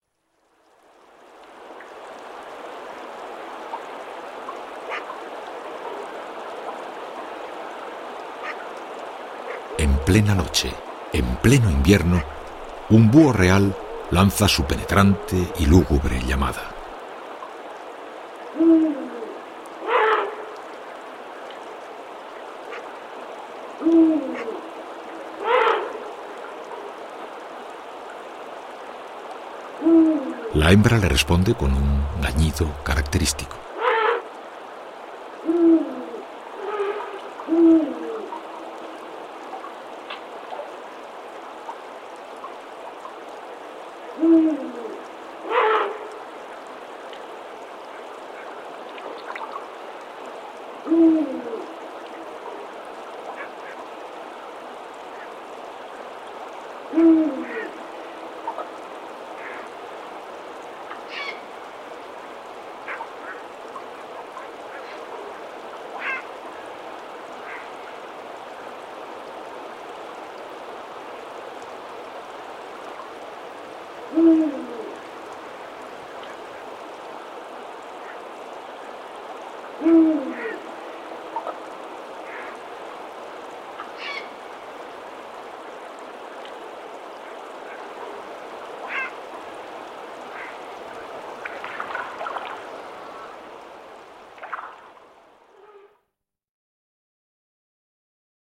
El Sonido de los Parques Nacionales es una colección realizada en el año 2003 por Carlos de Hita con las narraciones de Iñaki Gabilondo.
En Enero, la llamada del Búho Real en la sierra, tras el crepusculo (01:40)